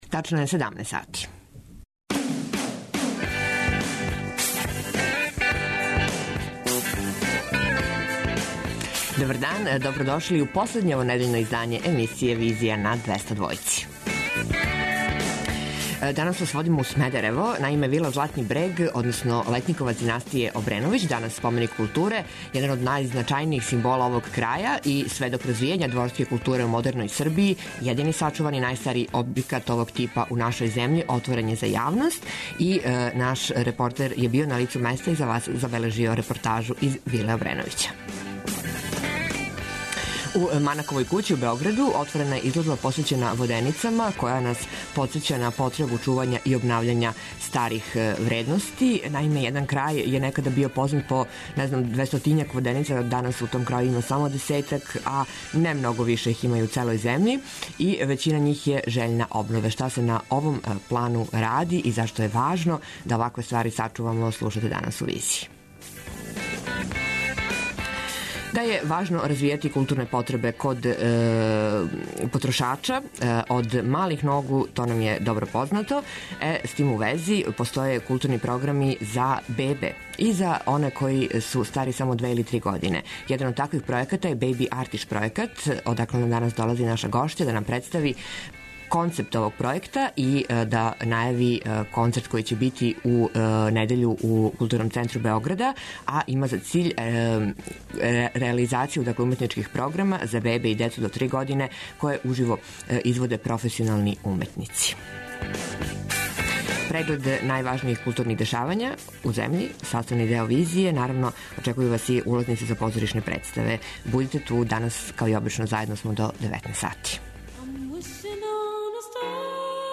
Вила је отворена за јавност и данас у Визији вам доносимо репортажу са лица места. У Манаковој кући, у Београду, отворена је изложба 'Подгрмечке воденице', која подсећа на потребу чувања и обнављања старих вредности.